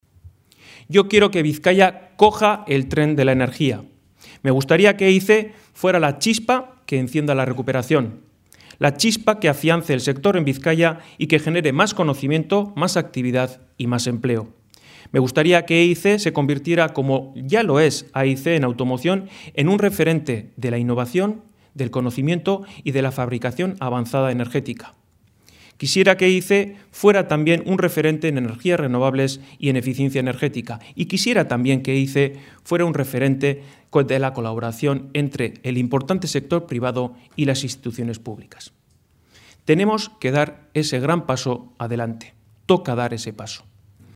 Unai Rementeria, candidato de EAJ-PNV a diputado general de Bizkaia, y Juan Mari Aburto, candidato jeltzale a la Alcaldía de Bilbao, han mantenido esta mañana en el hotel Meliá de Bilbao el último encuentro sectorial en el que han presentado ante una veintena de representantes empresariales y del sector económico del territorio y la capital sus propuestas en materia de reactivación económica y generación de empleo.